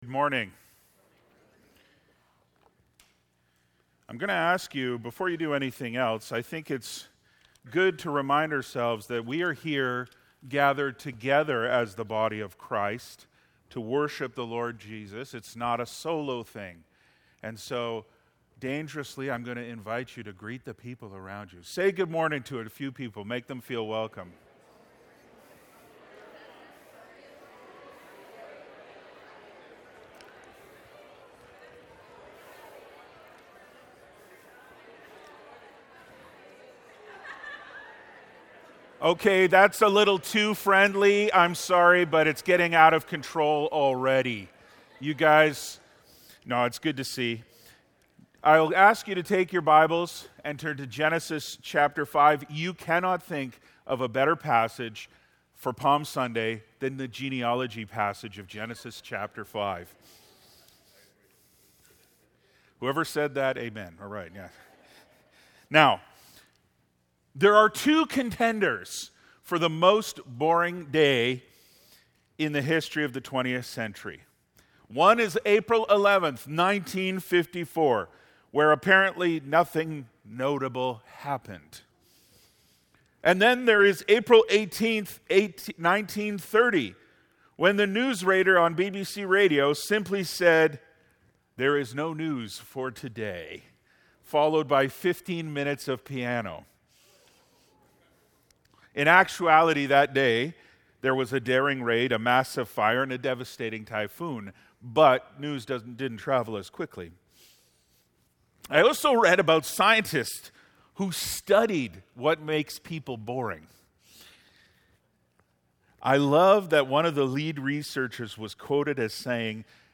Sermons | Faith Baptist Church